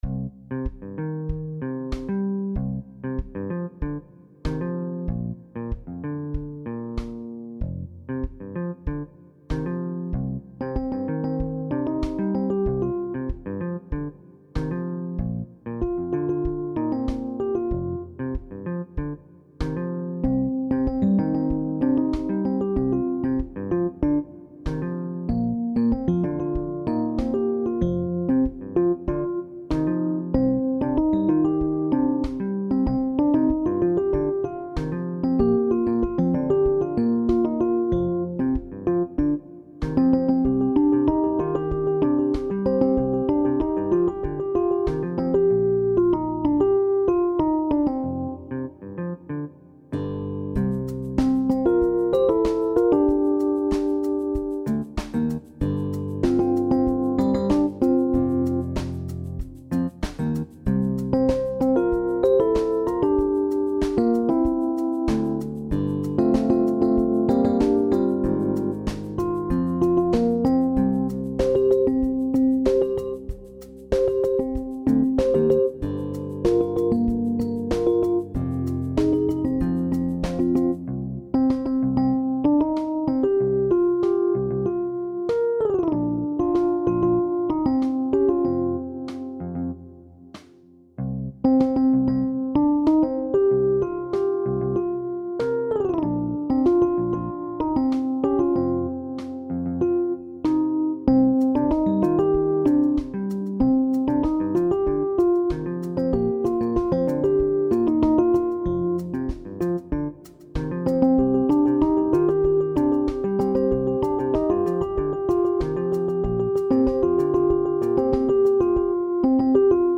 SSAA met piano